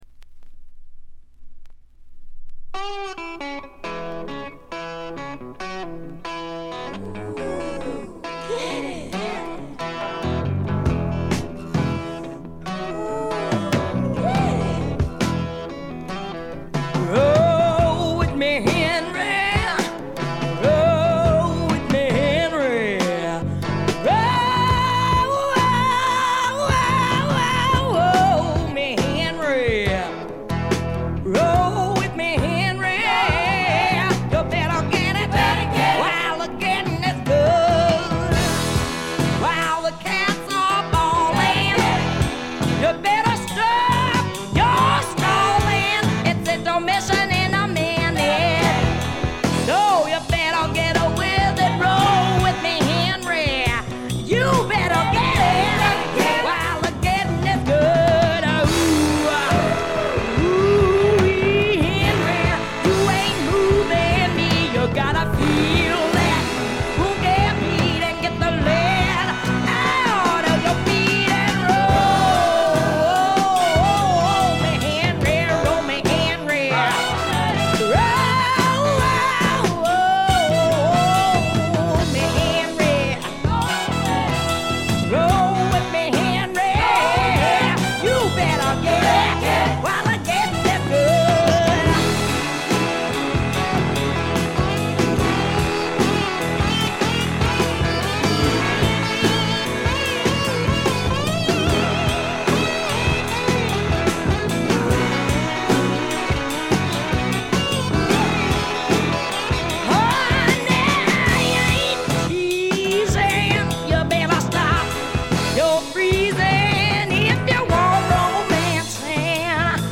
ほとんどノイズ感無し。
アーカンソー州出身の女性スワンパーが放ったフィメール・スワンプの大名盤です！
分厚いホーンセクションも心地よく決まって素晴らしいですね。
超重量級スワンプ名作。
試聴曲は現品からの取り込み音源です。